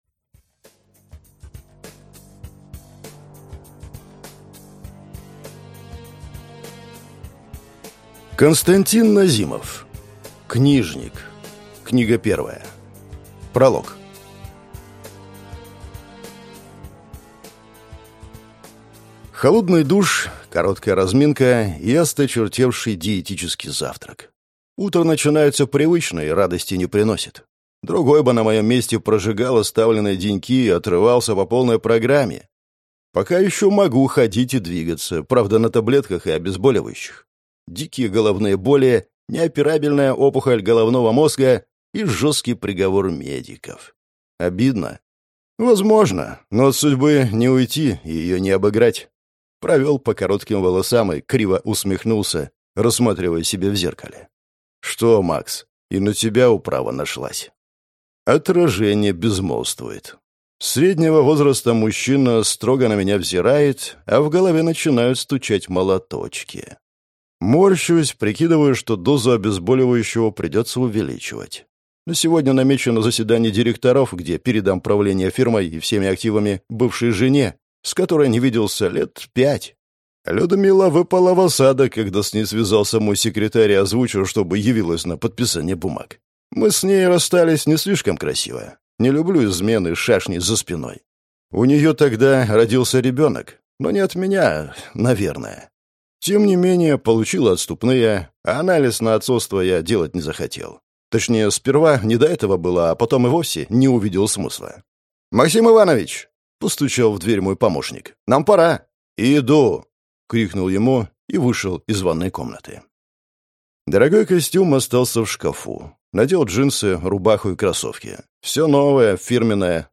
Аудиокнига Книжник.